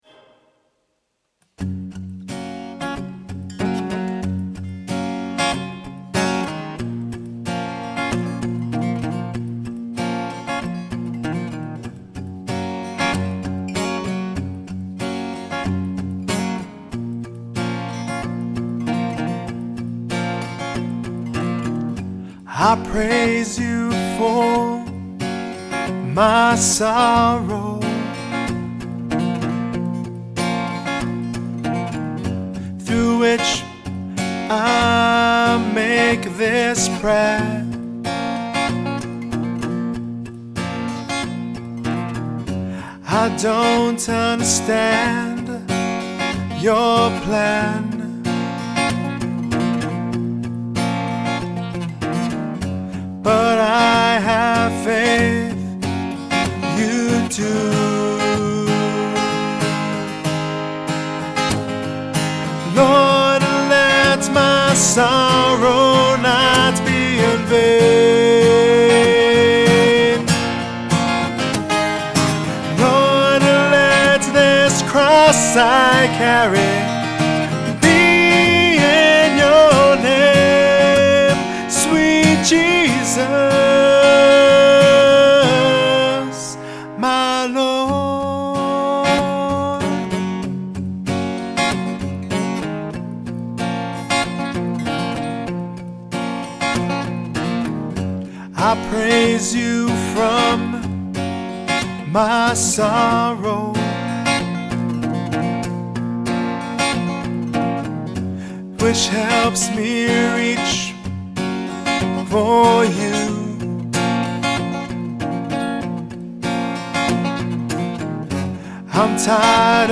Я хотел, чтобы запись звучала сыро и не отполировано. Я хотел, чтобы это имело живое и аутентичное чувство, и поэтому идея отслеживания всего сразу привлекала меня.
• MXL 603 с (на расстоянии 3 фута, направлено на звуковую скважину гитары) ... Presonus MP20 Jensen / Linear Technologies Операционный канал ... Echo Audiofire 1/4 "вход
Итак, я хотел бы получить некоторые мнения: у версии без сжатия слишком много "нежелательной" динамики?